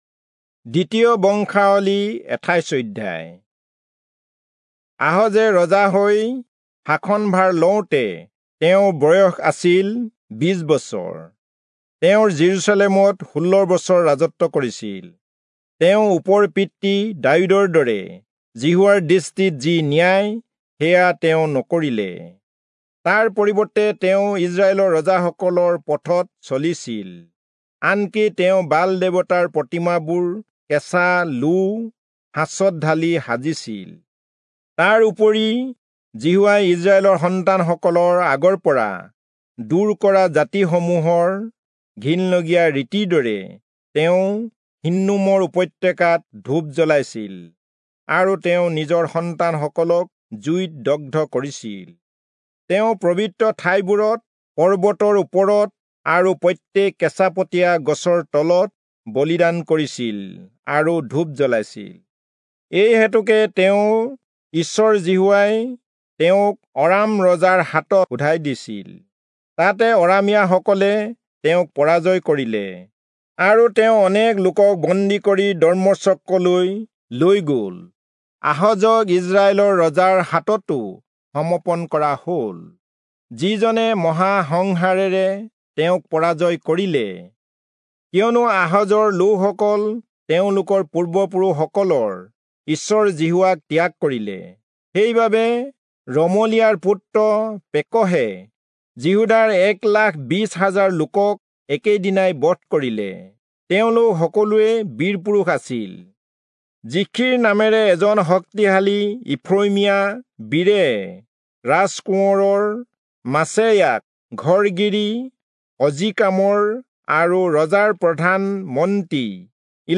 Assamese Audio Bible - 2-Chronicles 22 in Ervbn bible version